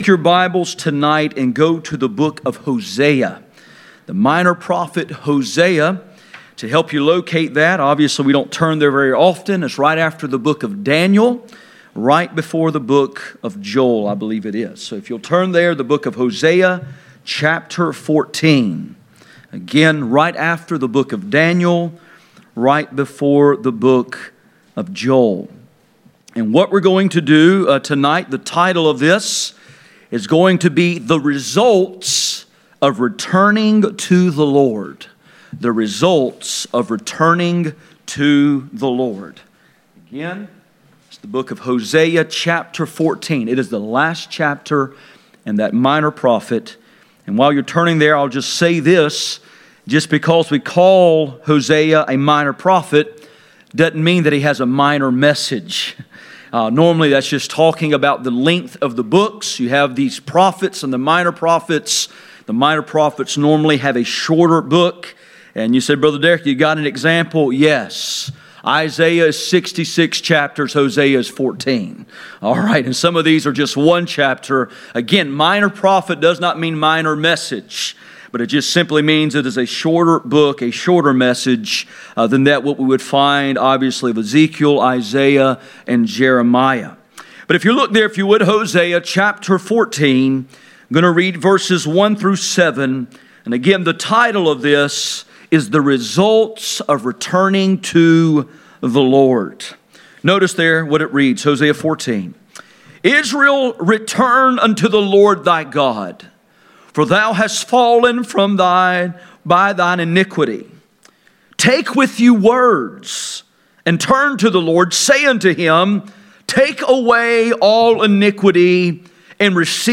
Hosea 14:1-7 Service Type: Sunday Evening %todo_render% « Destructive power of the Spirit of Power Persecution